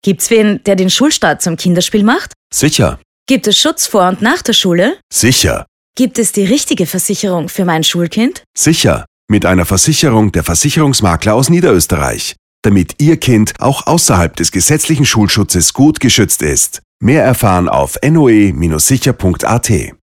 Ausstrahlung im Radio NÖ
Radiospot zum Nachhören © ORF NÖ